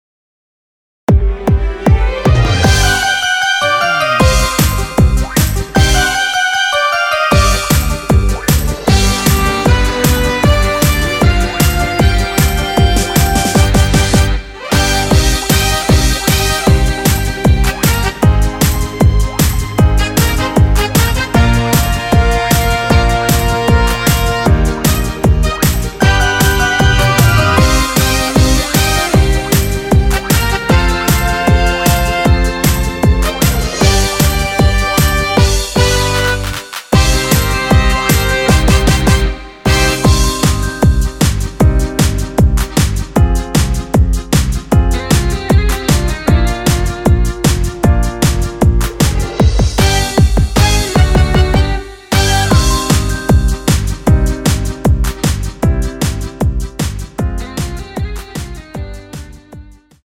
원키에서(-2)내린 MR입니다.
Cm
앞부분30초, 뒷부분30초씩 편집해서 올려 드리고 있습니다.
중간에 음이 끈어지고 다시 나오는 이유는